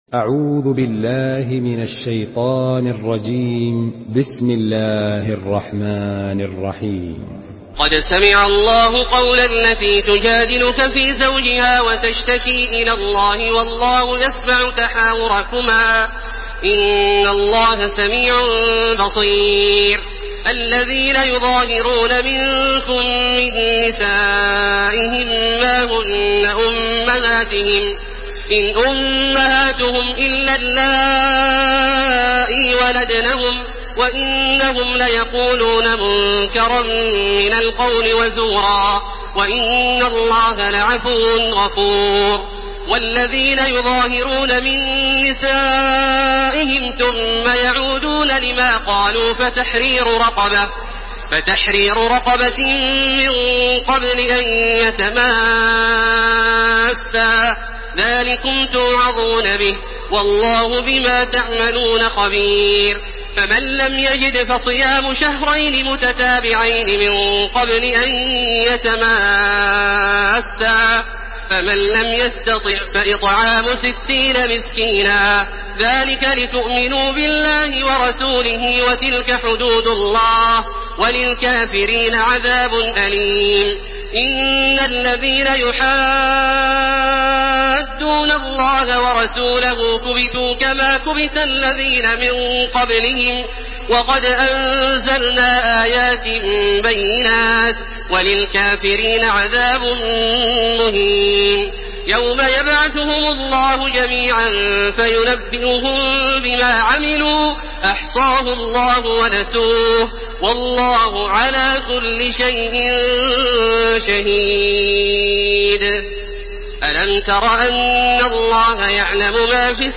تحميل سورة المجادلة بصوت تراويح الحرم المكي 1431
مرتل حفص عن عاصم